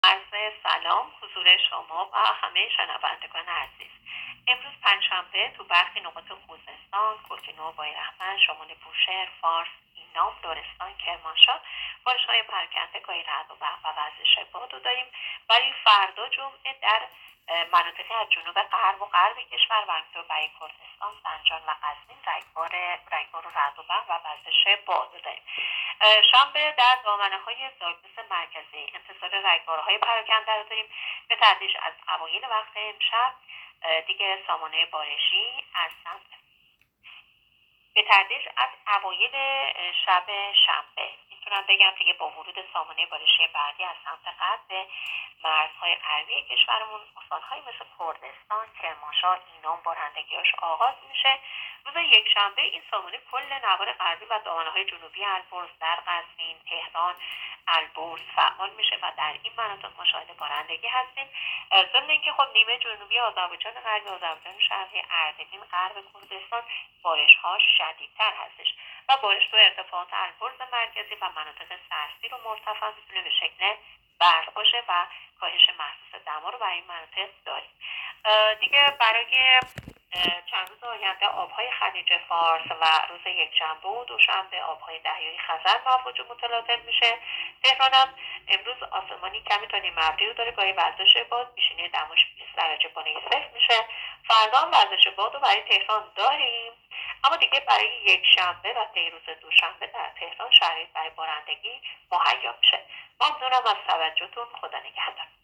گزارش رادیو اینترنتی پایگاه‌ خبری از آخرین وضعیت آب‌وهوای دهم آبان؛